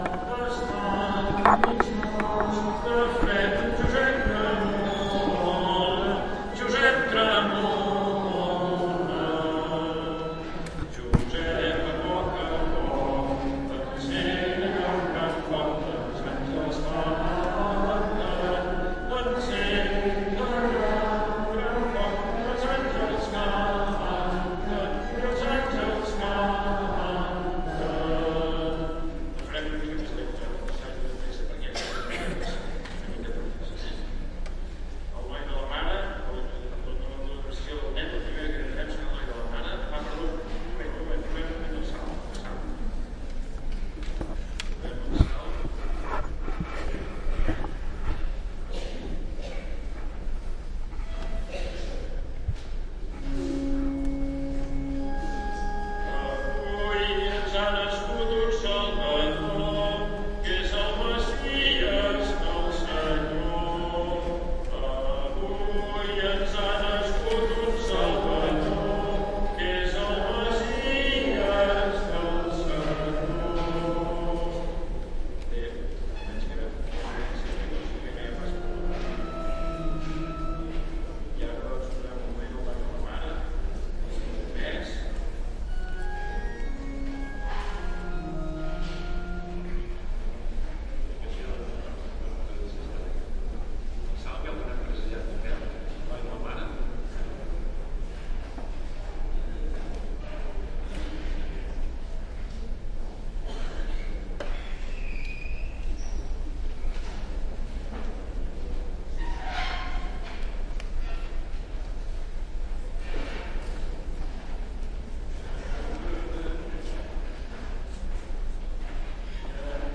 教堂风琴的声音。记录器：缩放H1，无需处理。
标签： 宗教 教堂 祈祷 达勒姆 宗教氛围 教堂 现场录制
声道立体声